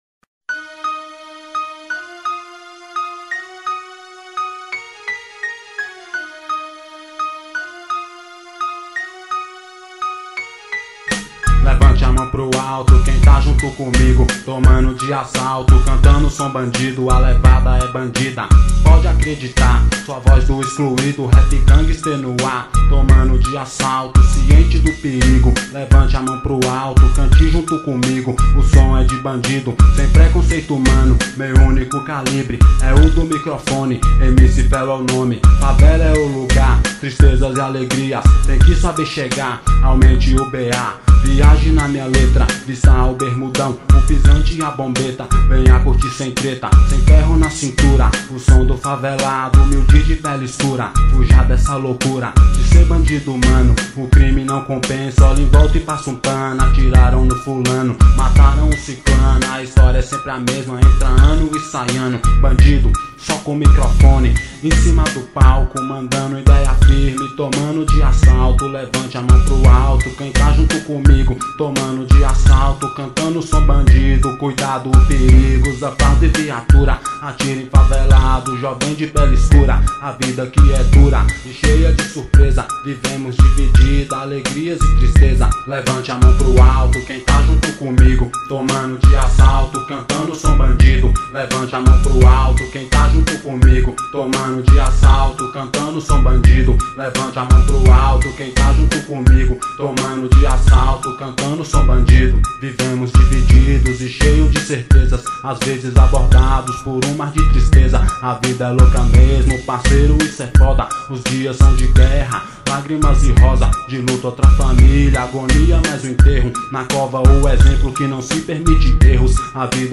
Rap.